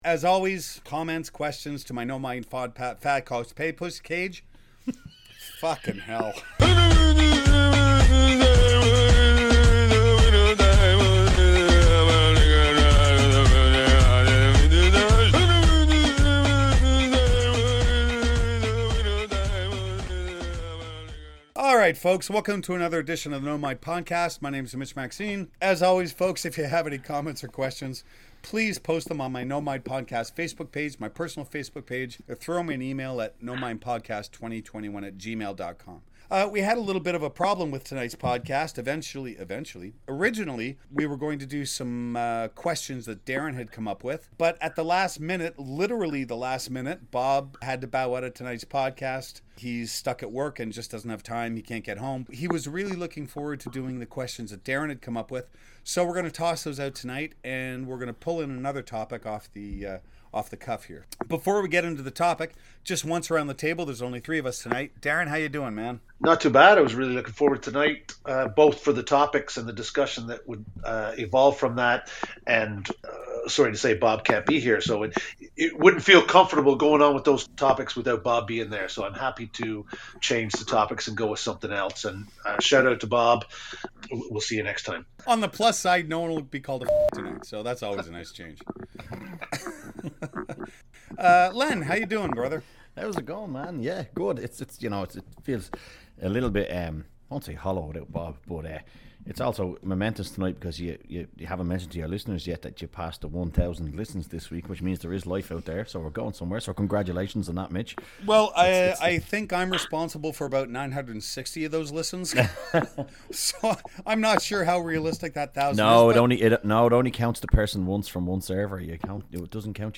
A discussion about the organizations that govern martial arts, and Karate in the Olympics.